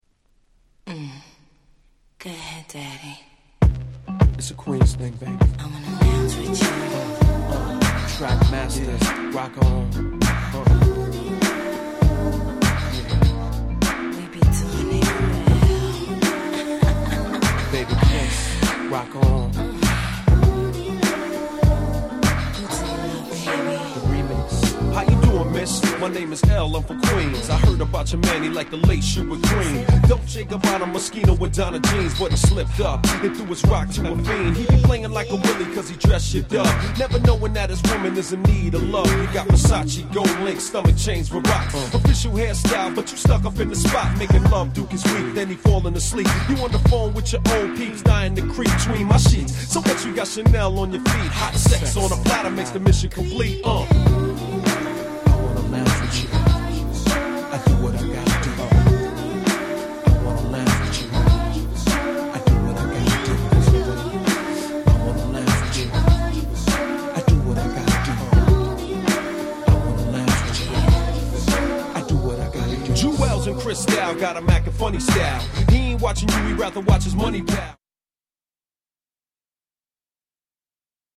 96' Big Hit Hip Hop !!
R&BでもHip Hopでもどちらでも使える便利な1曲♪
Boom Bap ブーンバップ